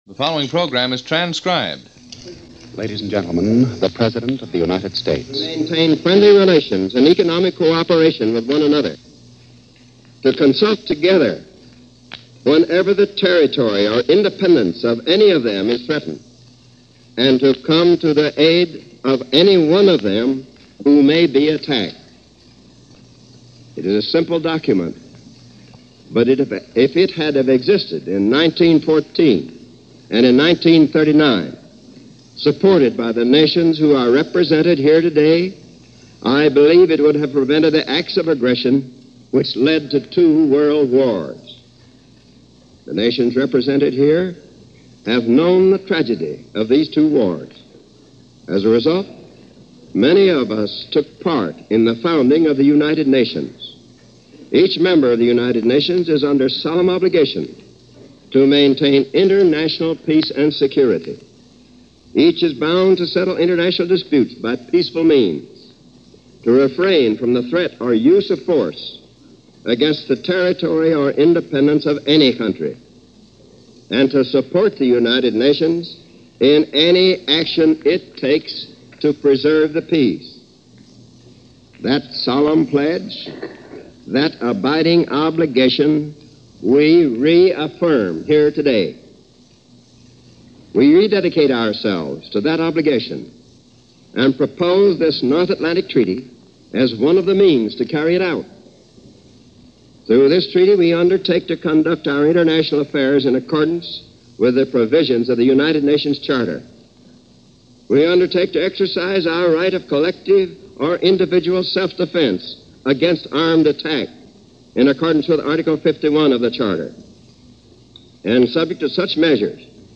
As a reminder of why NATO was an important step in the history of our Foreign Policy and why it was necessary to be formed, here is the complete address given by President Truman at the signing ceremony on April 4, 1949.